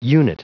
Prononciation du mot unit en anglais (fichier audio)
Prononciation du mot : unit